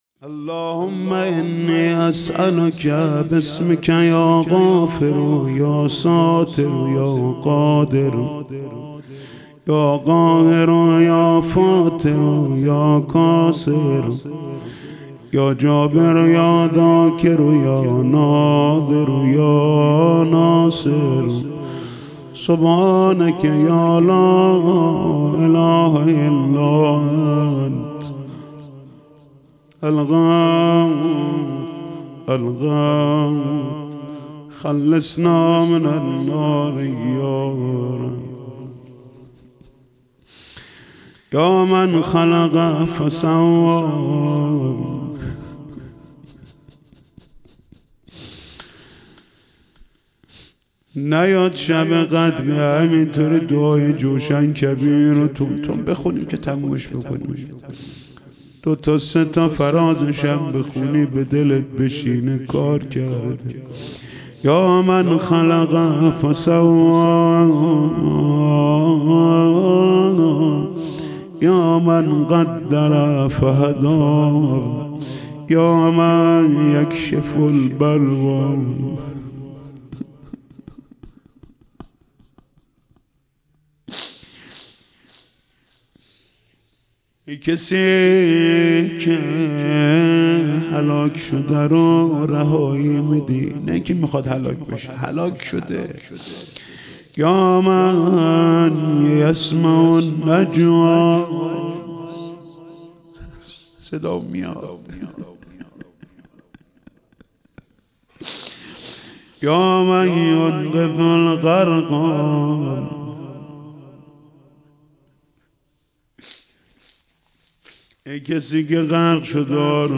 شب بیست و یکم رمضان 99 - قرائت دعای جوشن کبیر